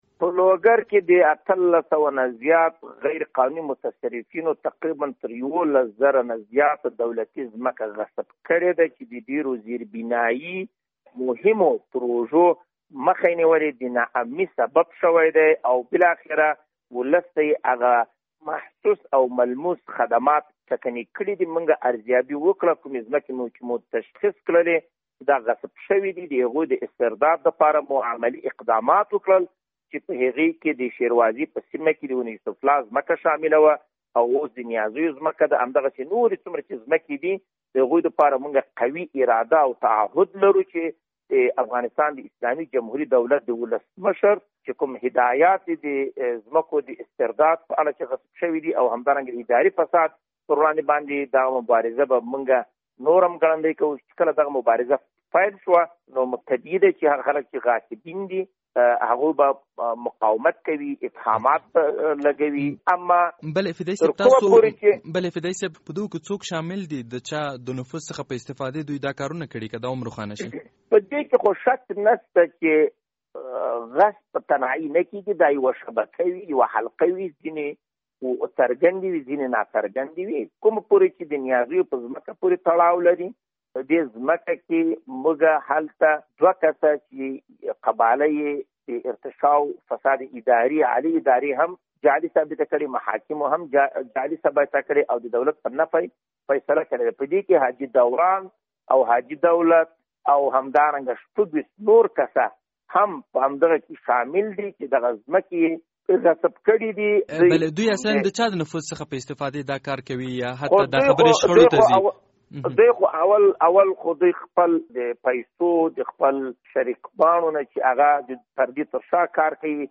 د لوګر له والي سره مرکه